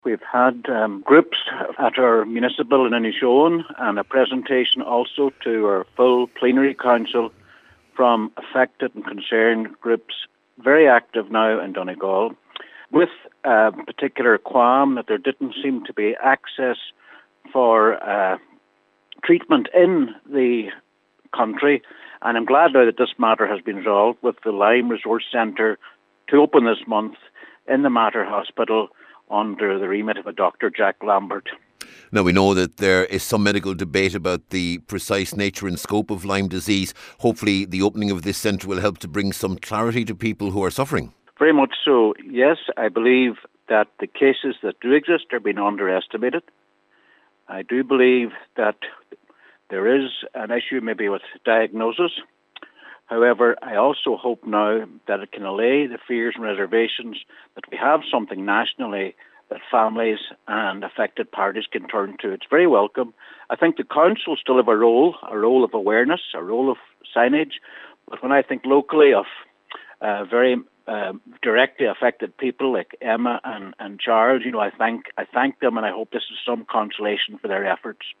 A Donegal councillor is welcoming confirmation that the National Lyme Disease Resource Centre will open in Dublin this month.